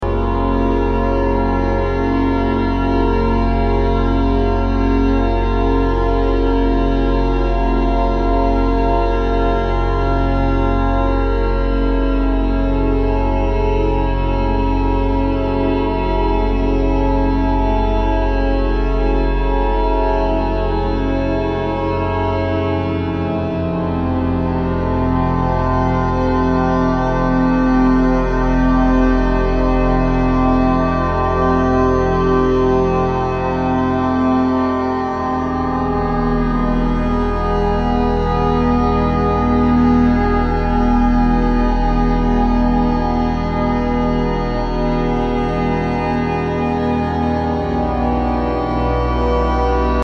Experimental >
Ambient, Drone >